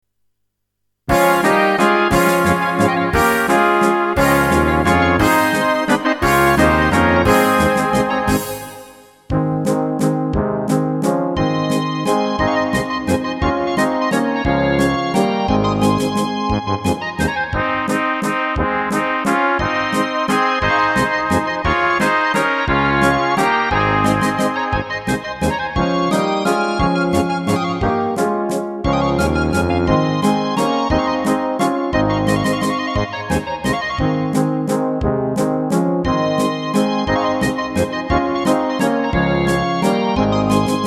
Rubrika: Národní, lidové, dechovka
- valčík
Karaoke